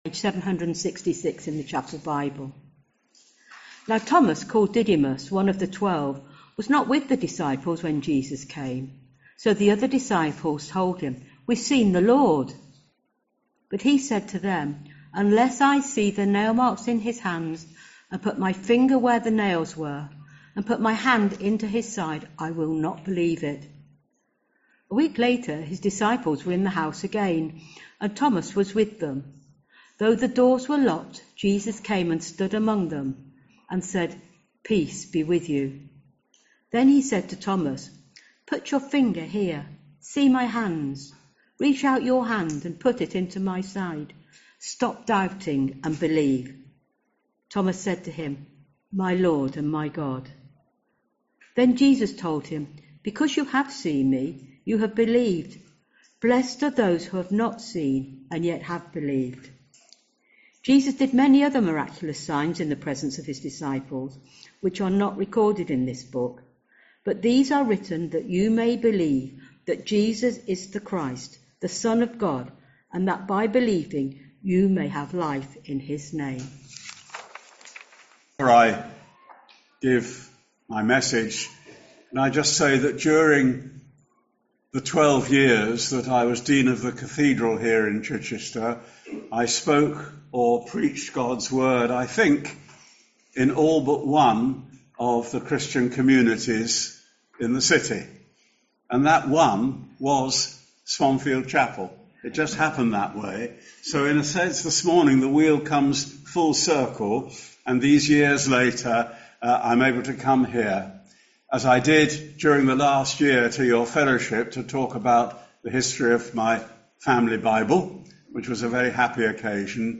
Sermons - Swanfield Chapel